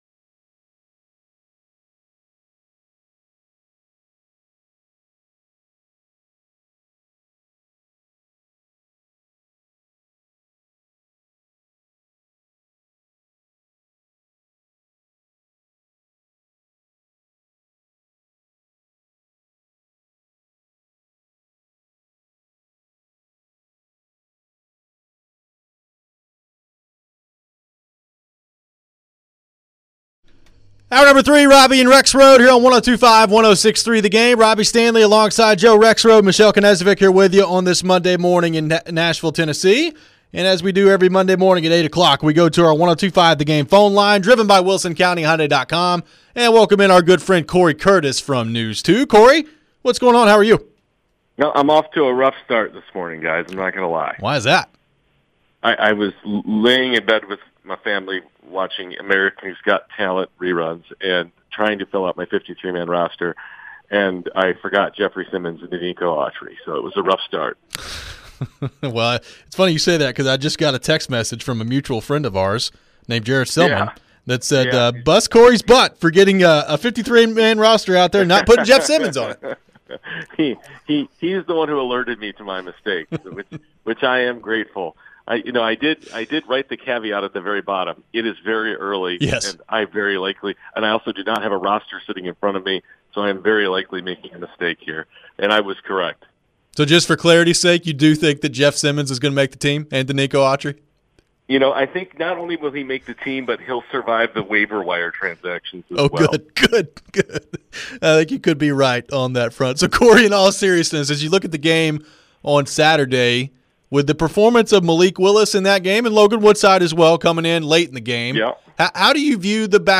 Who will win the backup QB job? We take your thoughts on the text line and phone line.